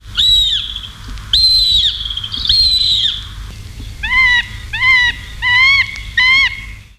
Autour des palombes
Accipiter gentilis